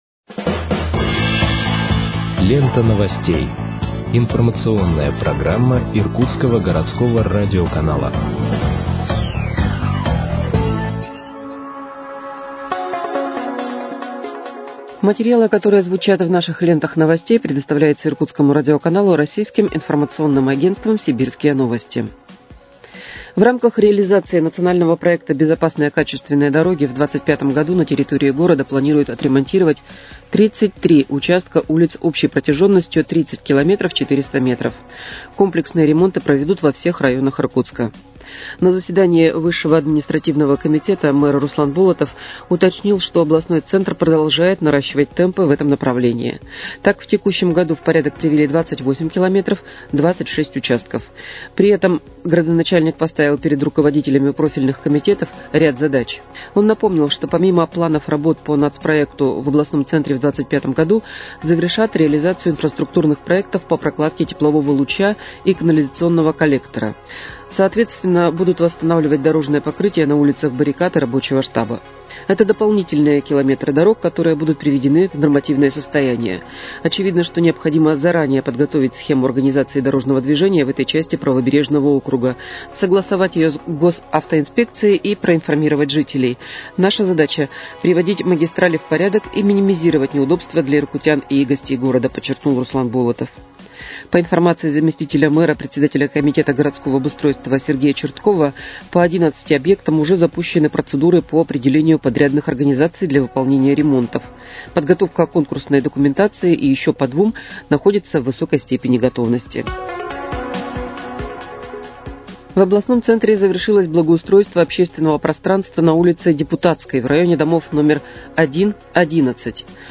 Выпуск новостей в подкастах газеты «Иркутск» от 03.12.2024 № 2